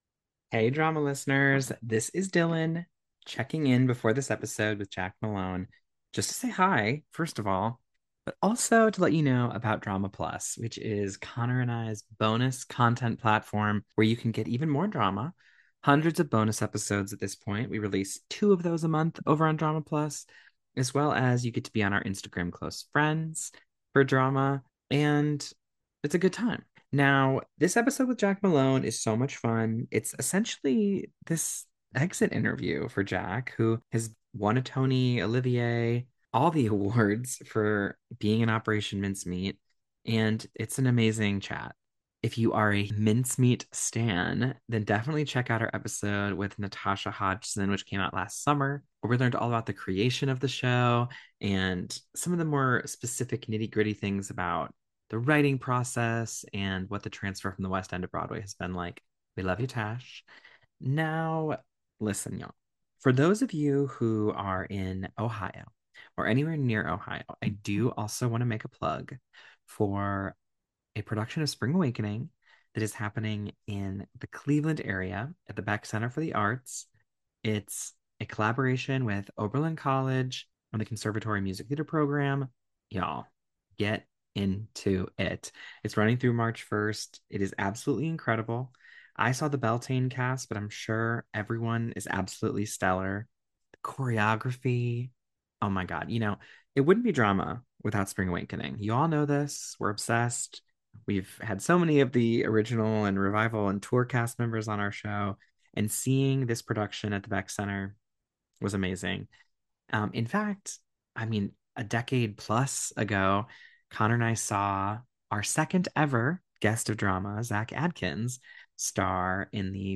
Ahead of the original Broadway cast’s final performance in Operation Mincemeat, the twins sit down with Jak for an unofficial DRAMA exit interview. Jak looks back on seven years of Mincemeat: from meeting the team behind it, the very first audition, and taking the show all the way to New York City.